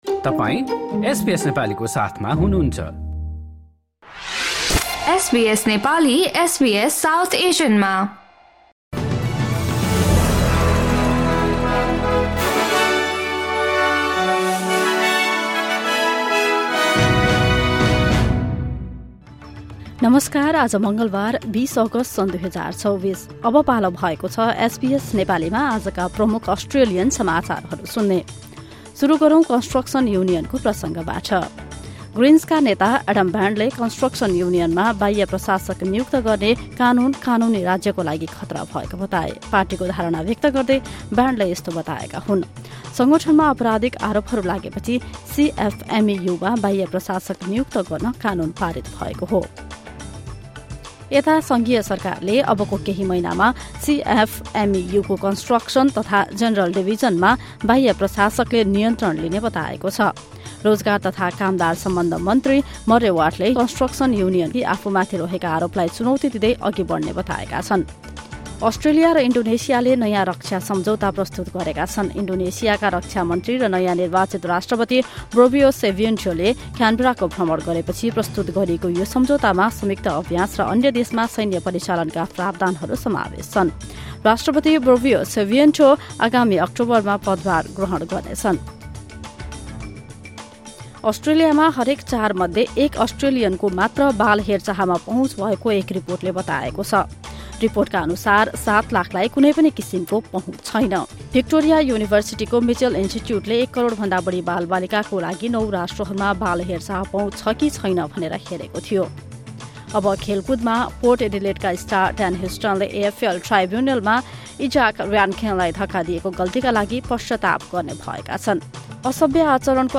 SBS Nepali Australian News Headlines: Tuesday, 20 August 2024